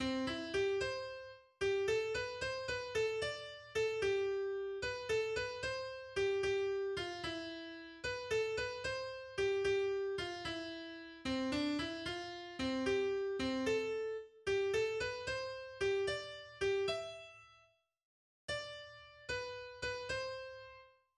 Studentenlied aus der Vormärzzeit